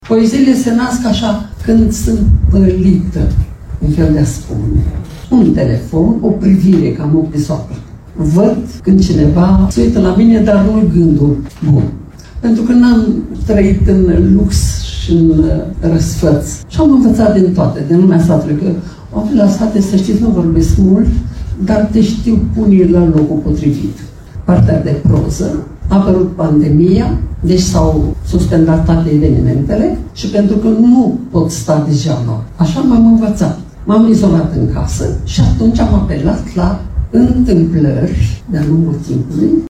Solista de muzică populară SOFIA VICOVEANCA a lansat, pe 3 august, la Biblioteca Bucovinei din Suceava, volumul „Drum de cântec și credință”.
În vârstă de 81 ani, ea a spus auditoriului prezent la lansare că această carte a fost inspirată “de întâmplările vesele și triste din viață”.